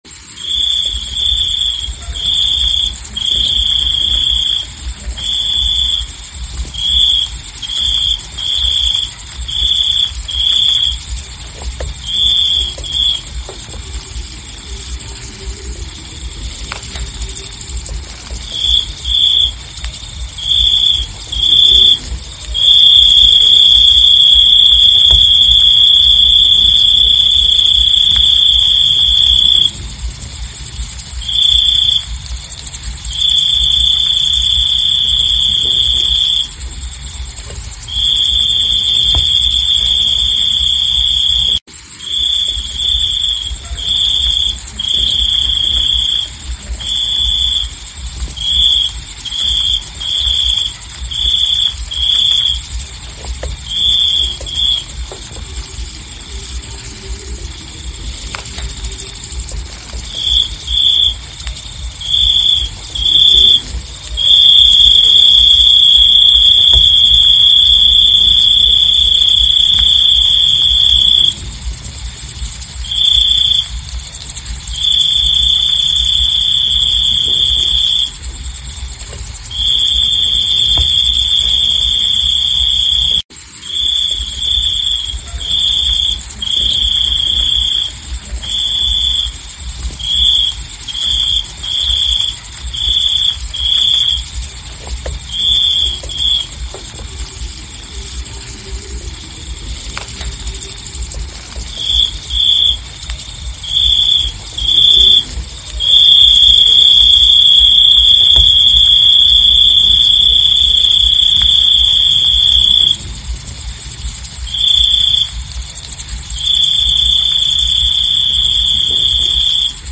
เสียงตั๊กแตนปาทังก้า
หมวดหมู่: เสียงสัตว์ป่า
คำอธิบาย: ฟังเสียงตั๊กแตนปาทังก้า (Bombay Locust) Patanga succincta กำลังหาคู่อยู่นี่, ดาวน์โหลดเสียงตั๊กแตนปาทังก้า MP3 ที่นี่
tieng-chau-chau-bombay-th-www_tiengdong_com.mp3